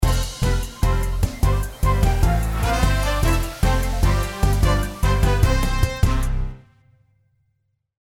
ジングル